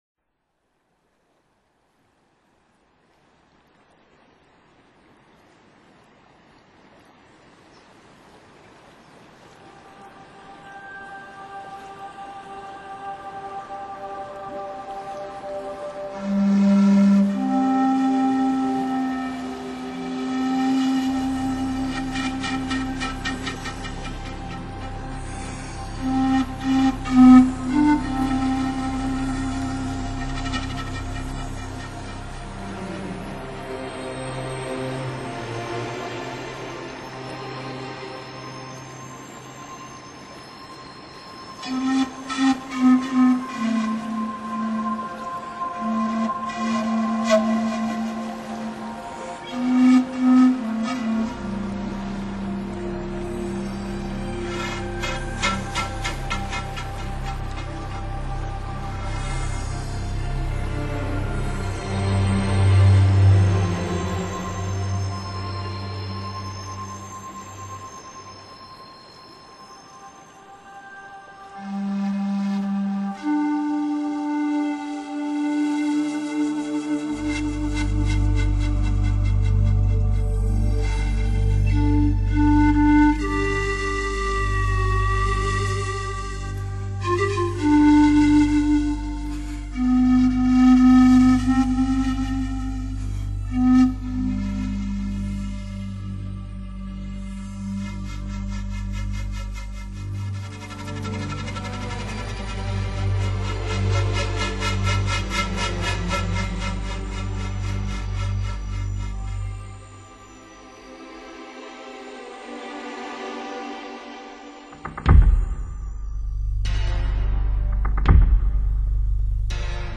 包括弯萧、弓笛、印度 横笛、缅甸竖笛、鹰骨笛、日本尺八、木埙、陶埙等，充满迷人的东方色彩
气势宏伟的 爆棚效果、纤细入微层次错落的人声颤音、饱满圆润空气感十足的乐器音色， 对于音响的表现无一不是一种考验。